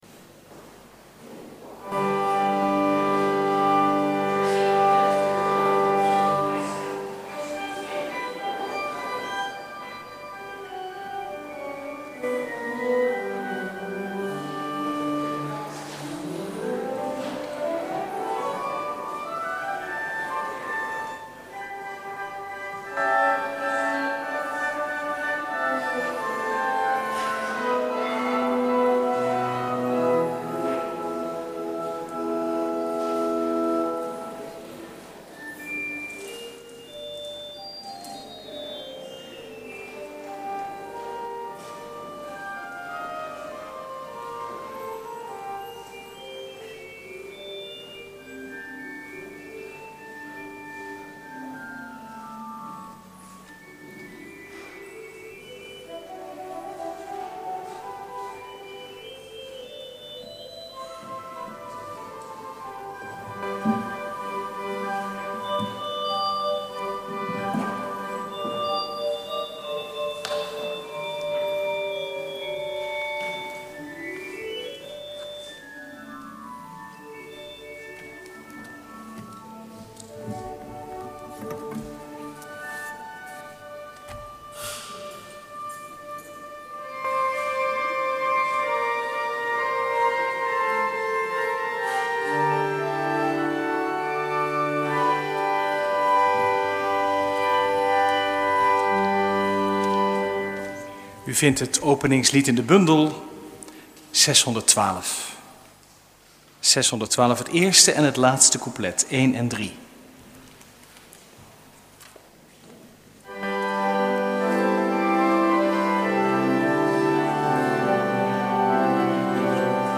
Preek 23e zondag, door het jaar A, 3/4 september 2011 | Hagenpreken